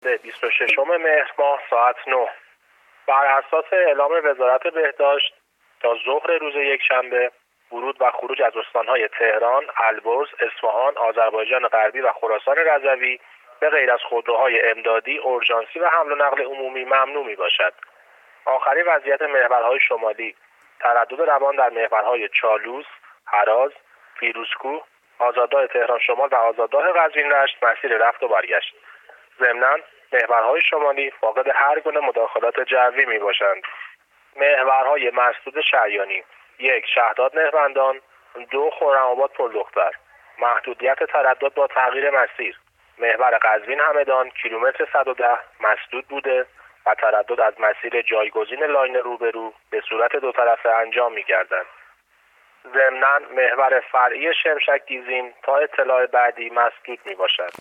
گزارش رادیو اینترنتی از وضعیت ترافیکی جاده‌ها تا ساعت ۹ روز شنبه ۲۶ مهر